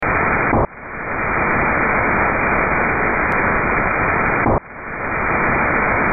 Dopo la lettura di questo documento ho voluto provare a ricevere questo tipo di segnali utilizzando il ricevitore SDR Perseus e un'antenna verticale di 7 metri.
Ecco invece un singolo burst ricevuto sui 14763 khz , la durata è di circa 10 millisecondi e la larghezza di banda circa 3 khz.
è disponibile la registazione della demodulazione in Usb del segnale.
hf_burst.mp3